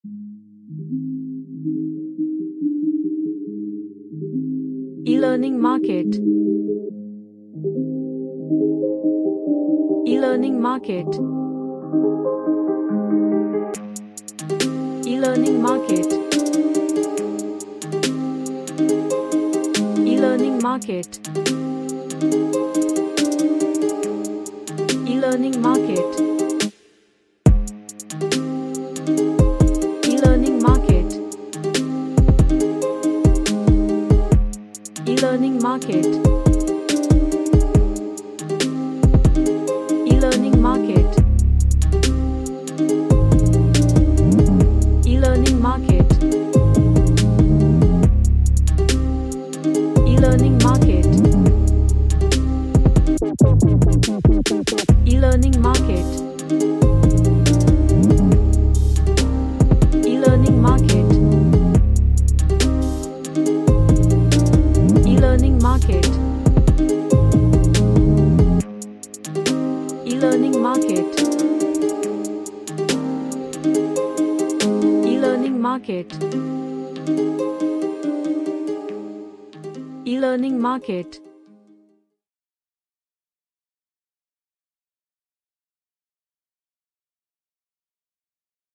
A mystical rap track
Magical / Mystical